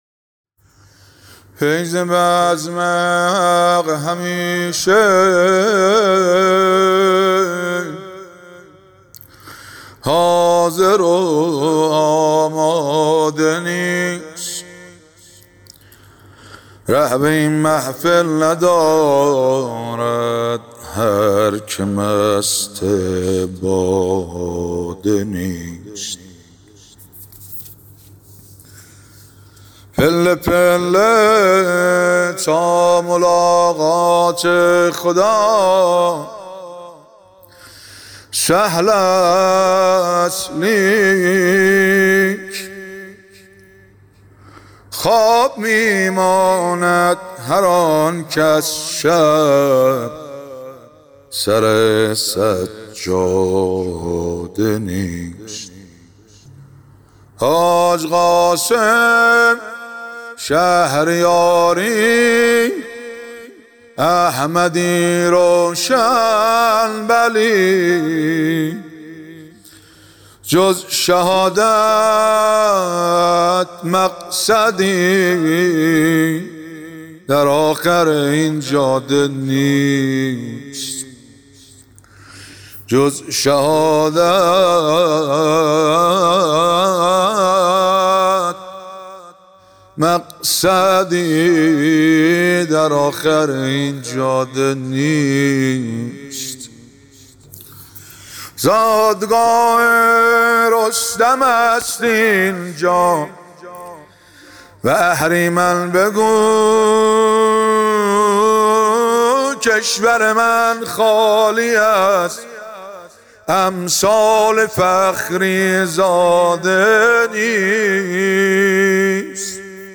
در هیئت فاطمیون قم شعرخوانی کرد.